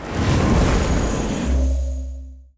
x1_battle_ageliusi_skill_01.wav